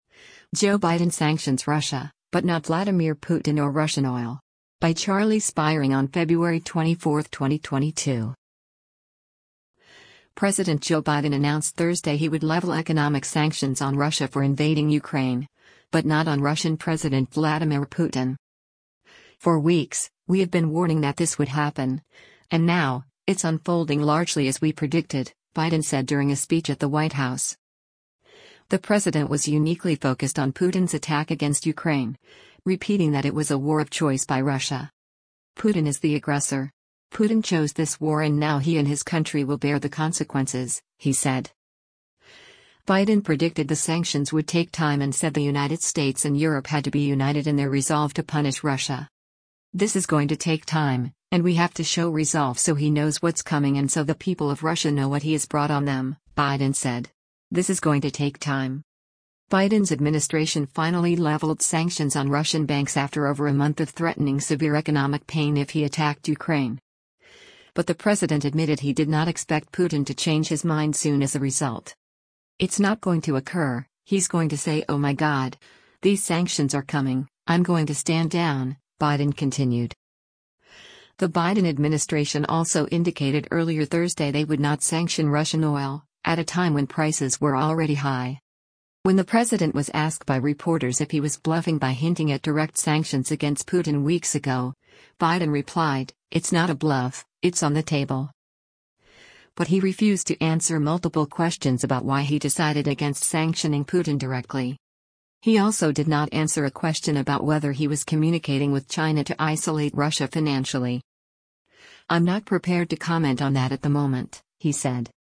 US President Joe Biden makes a statement from the East Room of the White House about Russi